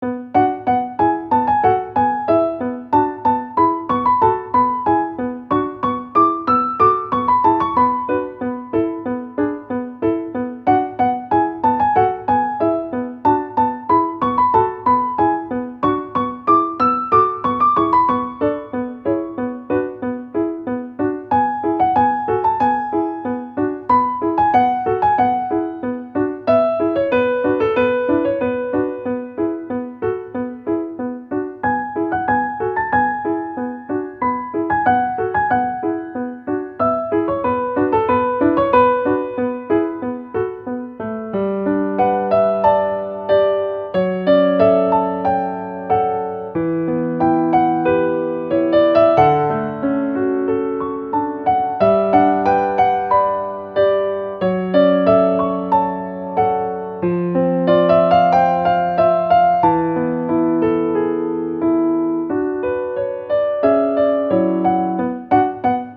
-oggをループ化-   寂しい しみじみ 1:44 mp3